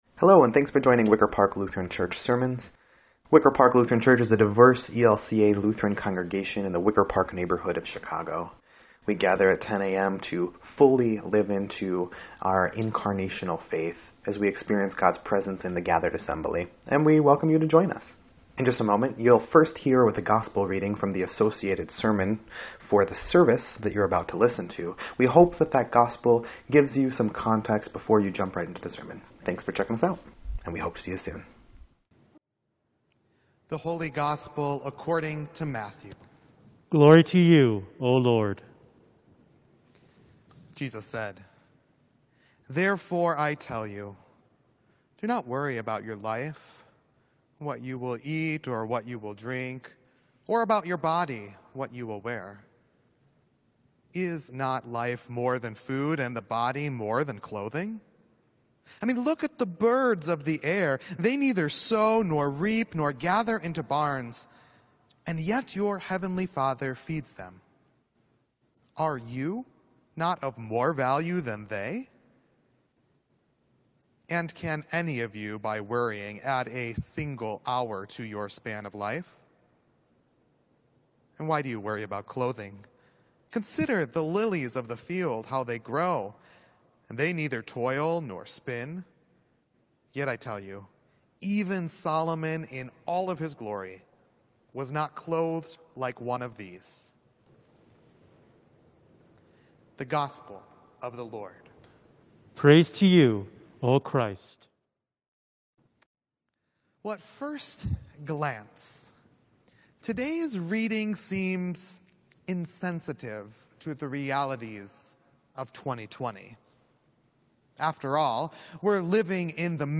10.4.20_Sermon_EDIT.mp3